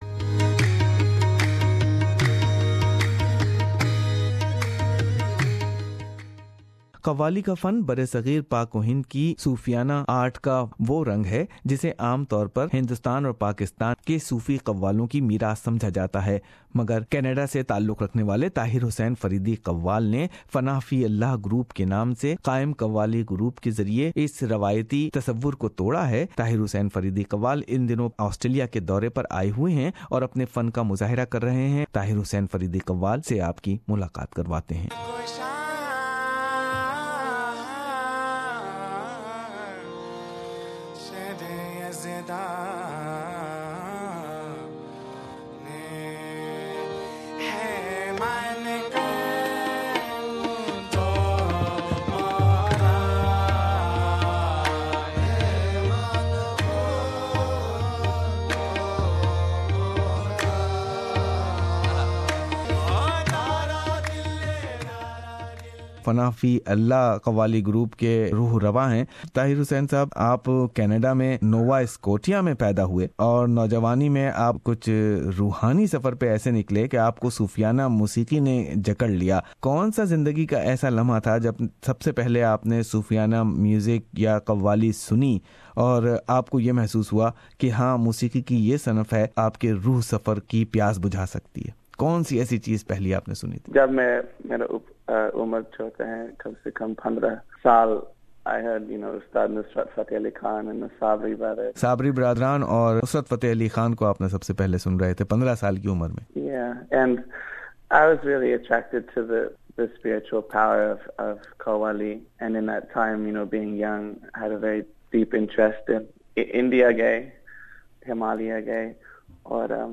Listen a very personal talk of a sufi qawali singer from the west.